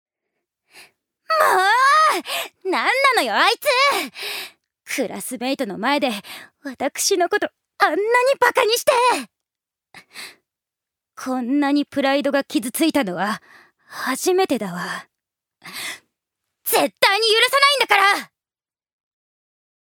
女性タレント
セリフ３